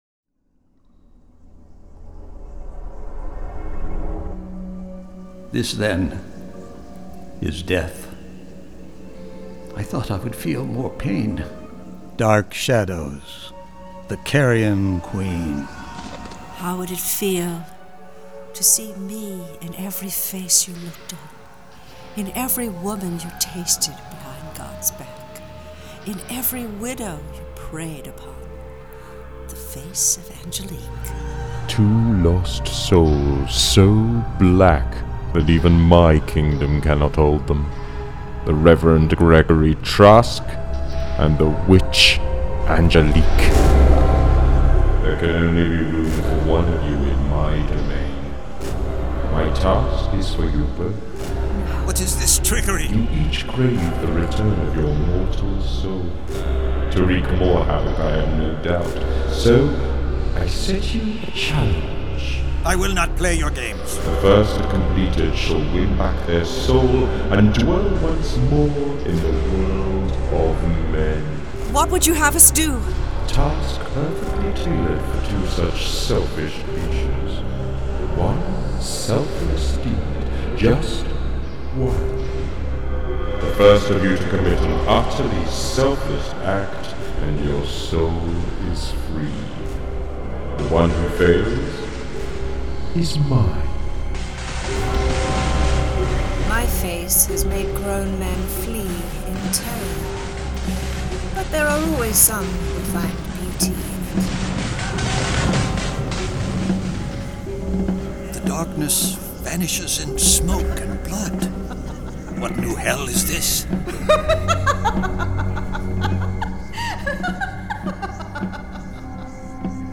Dark Shadows - Dramatised Readings 18. Dark Shadows: The Carrion Queen